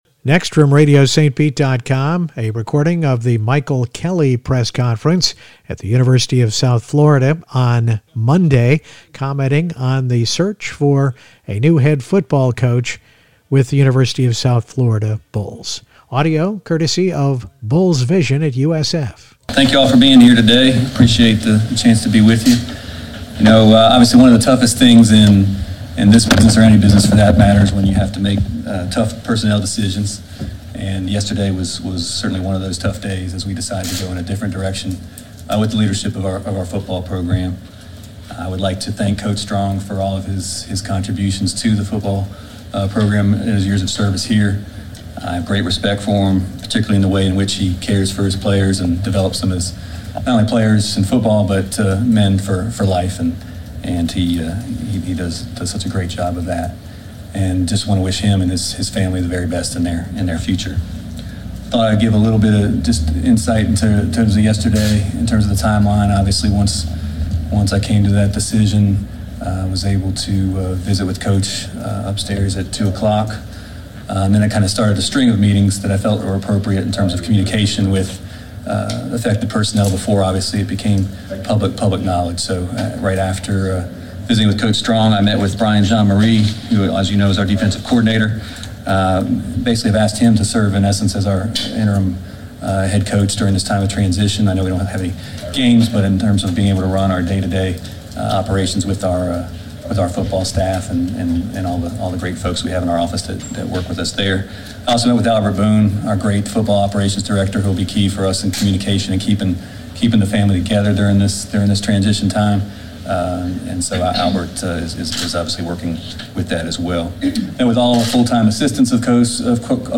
Press Conferences